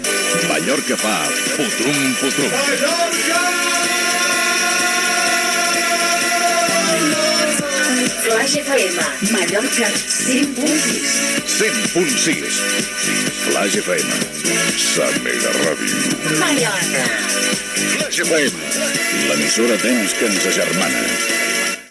Indicatiu de l'emissora a Palma de Mallorca.